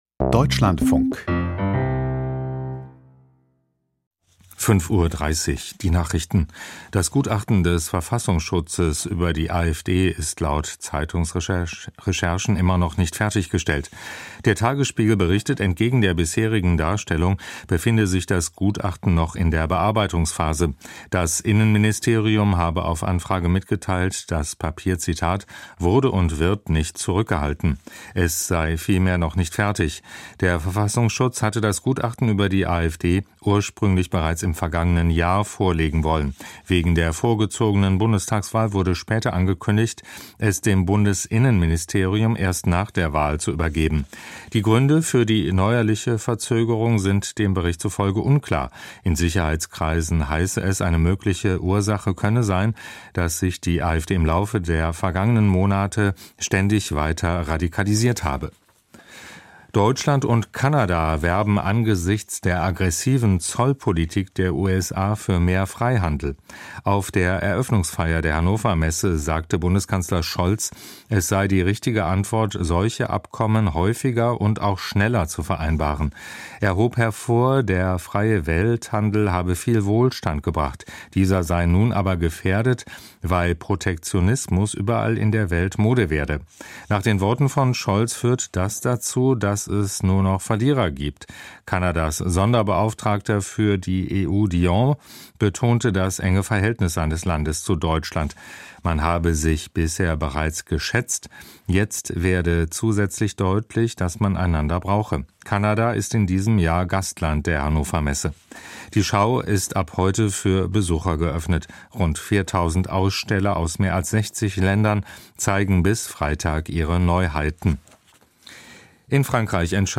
Die Nachrichten